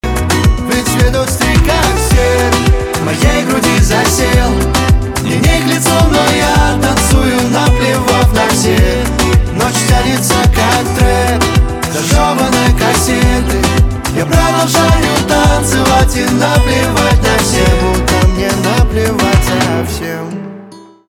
поп
гитара
позитивные